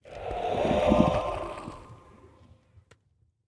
ambient_ominous1.ogg